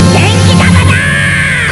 In questa pagina potete trovare i suoni in formato WAV / MP3 dei vari attacchi e delle tecniche speciali dei personaggi.